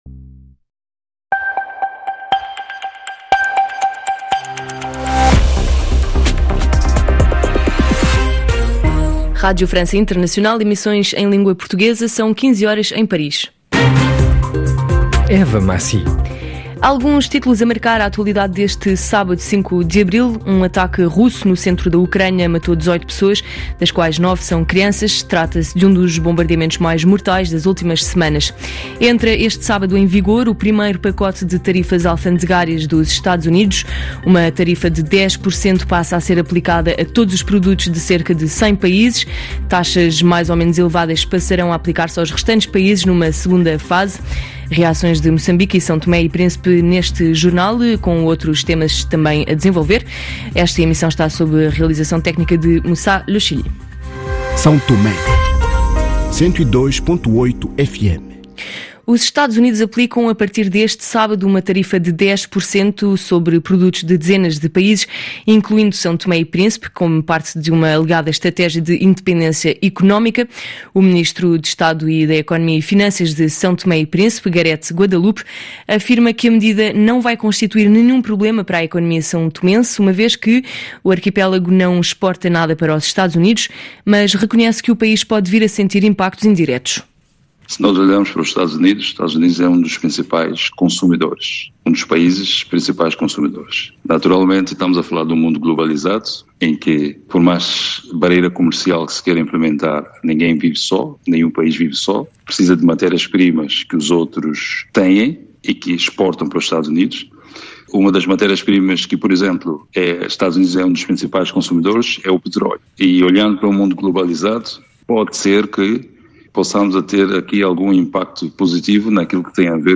Todos os noticiários e Podcast - Radio France Internationale - RFI - Noticiário 05/04 13h00 TMG